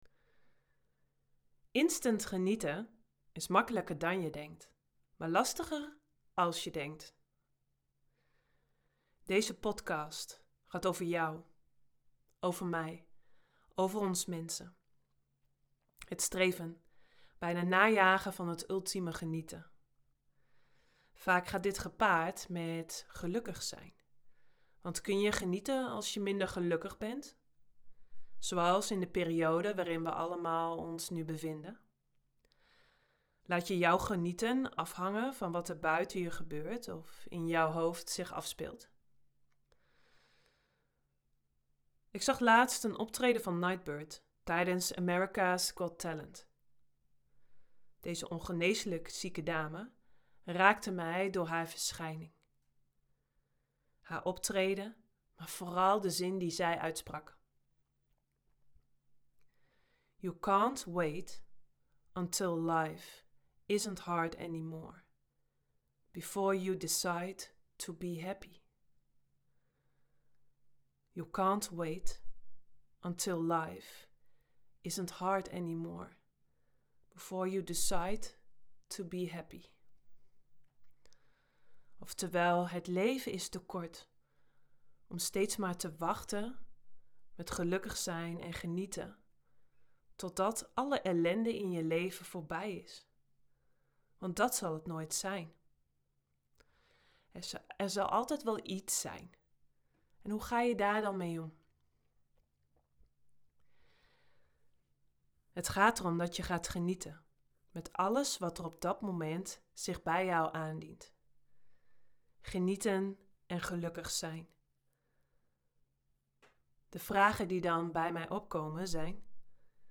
een fijne meditatie met muziek